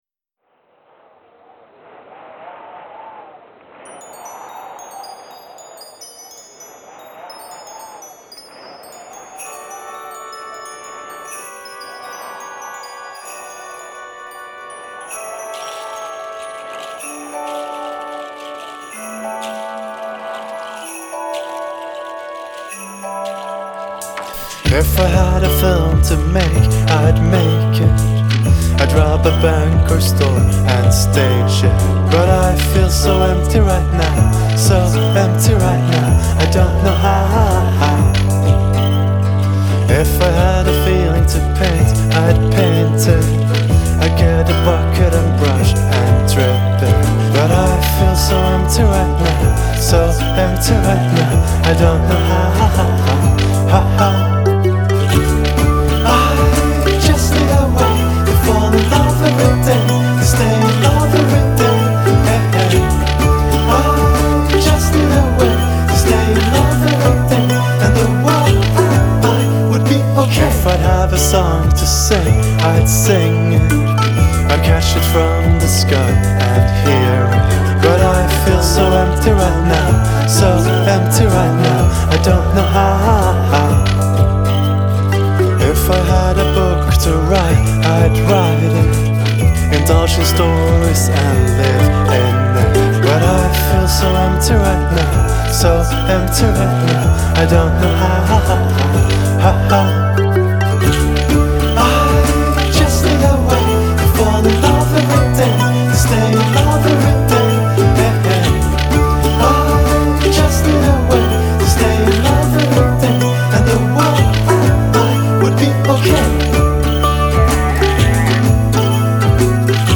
Indiepopband